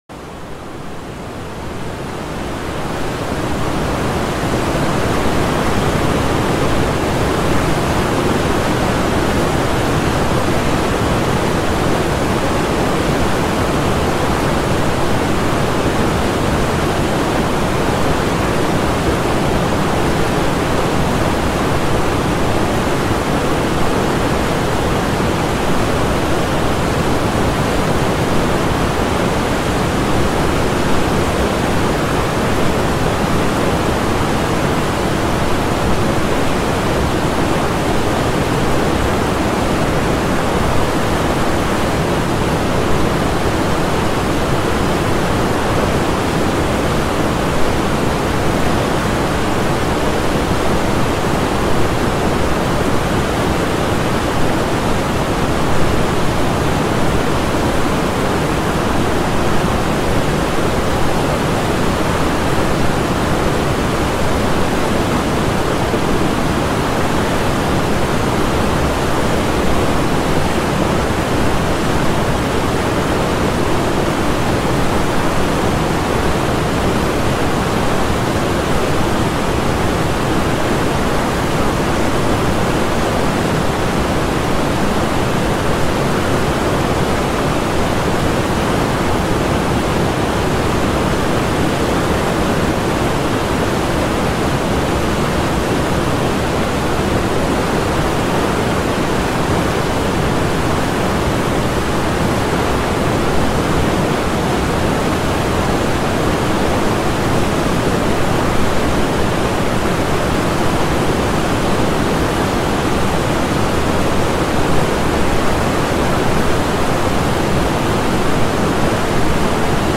دانلود آهنگ رودخانه کوهستانی و پرقدرت از افکت صوتی طبیعت و محیط
دانلود صدای رودخانه کوهستانی و پرقدرت از ساعد نیوز با لینک مستقیم و کیفیت بالا